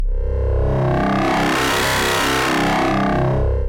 怪物咆哮 Fx
描述：一个用sytrus制作的dubsteppy growl fx。
Tag: 130 bpm Dubstep Loops Fx Loops 637.76 KB wav Key : Unknown